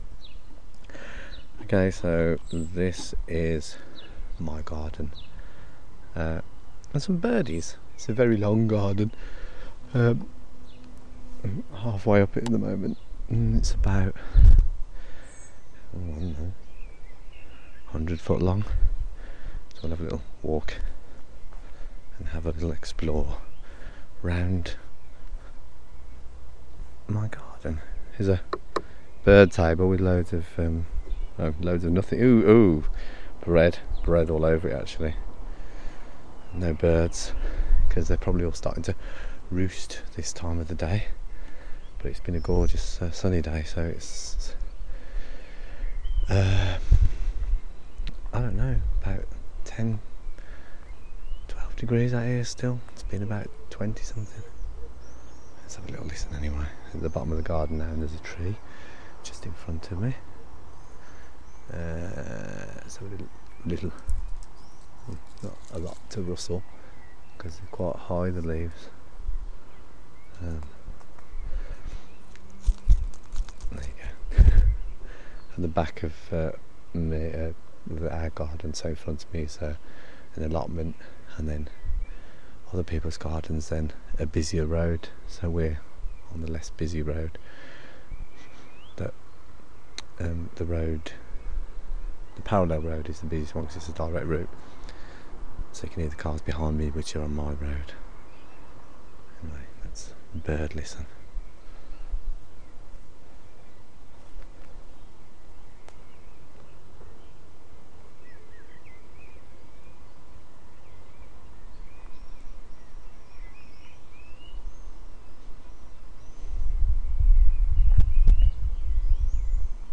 My garden at the end of the day. Birds, me and general niceness... Enjoy!